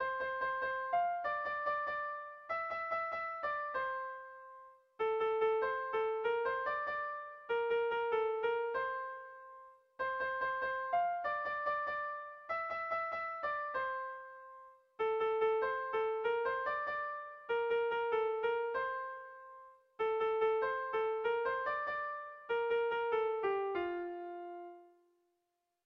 Sentimenduzkoa
Zortziko txikia (hg) / Lau puntuko txikia (ip)
AB1AB2